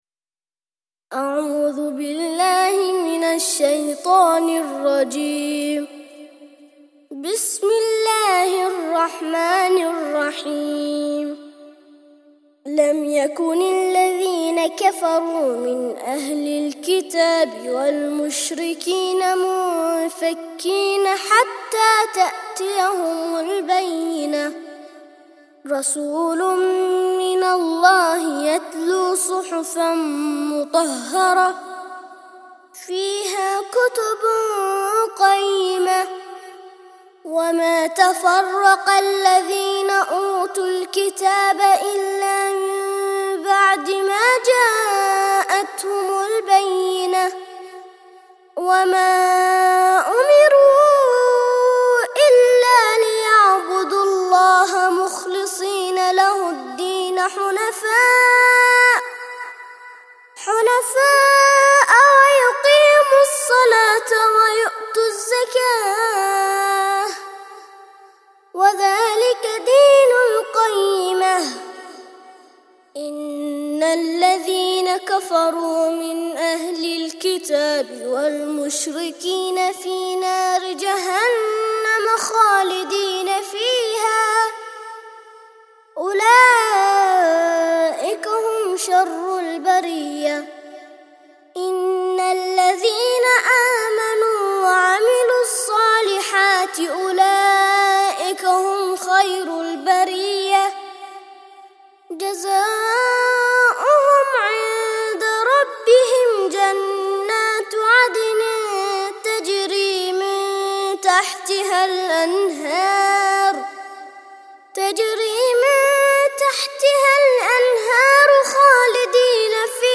98- سورة البينة - ترتيل سورة البينة للأطفال لحفظ الملف في مجلد خاص اضغط بالزر الأيمن هنا ثم اختر (حفظ الهدف باسم - Save Target As) واختر المكان المناسب